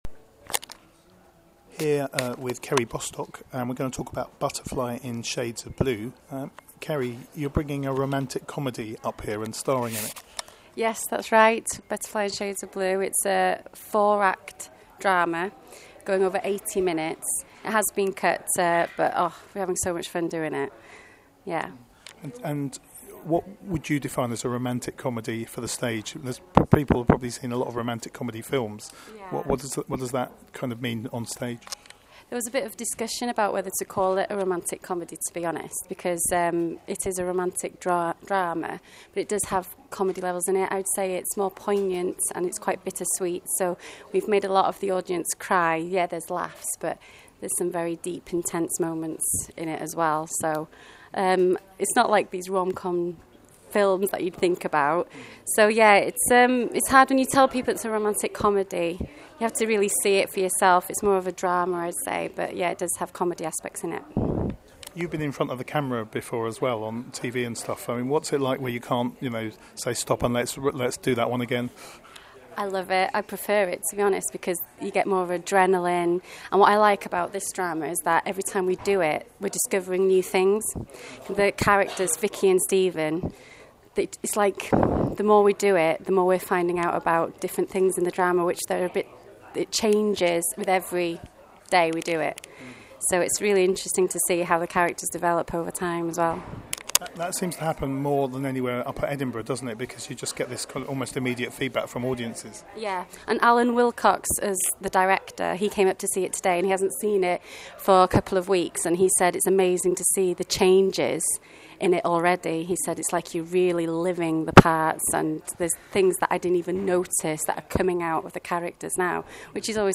Edinburgh Audio 2014